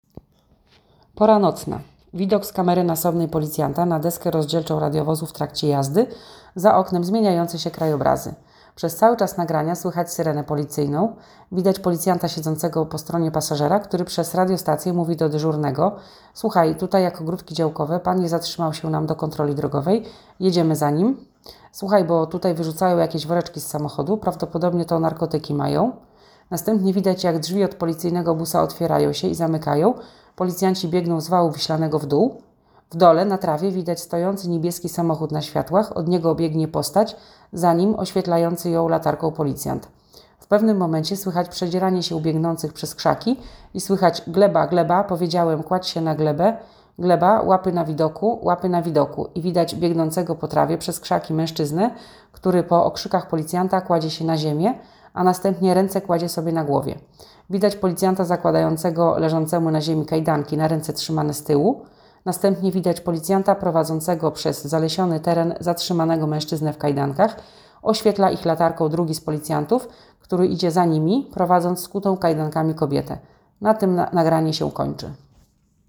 Deskrypcja filmu z pościgu
Nagranie audio audiodeskrypcja_filmu_z_poscigu.m4a